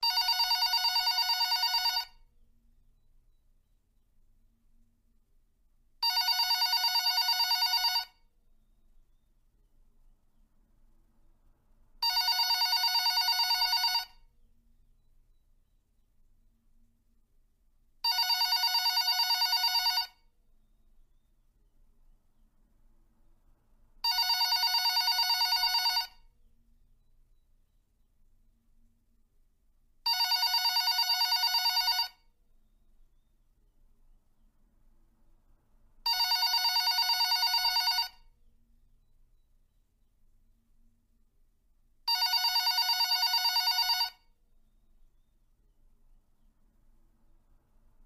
Klingelton Telefon 90er
Office Phone Klingelton Altes Telefon Klingelton
Beschreibung: Lade dir jetzt den Klingelton Telefon 90er kostenlos im MP3-Format herunter und erlebe den klassischen Sound der 90er Jahre!
klingelton-telefon-90er-de-www_tiengdong_com.mp3